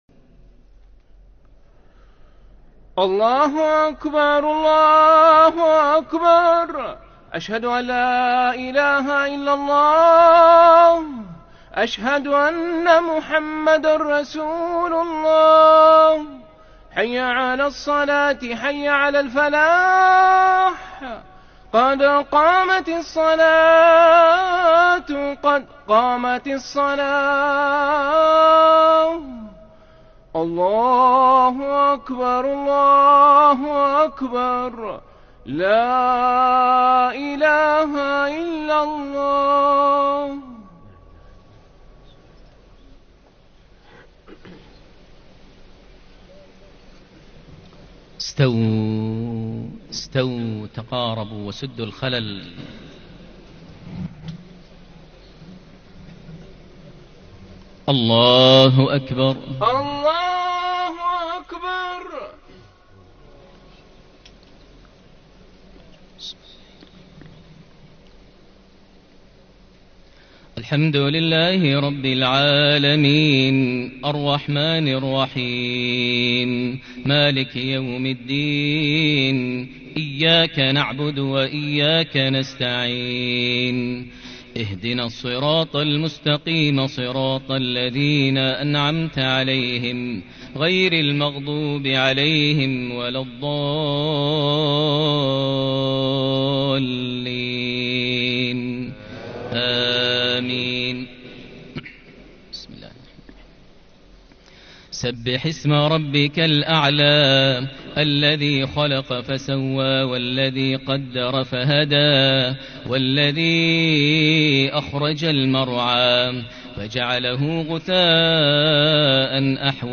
صلاة الجمعة 15 رجب 1440هـ سورتي الأعلى والغاشية > 1440 هـ > الفروض - تلاوات ماهر المعيقلي